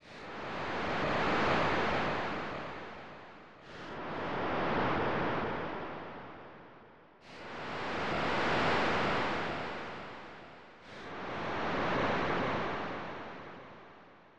Wave.wav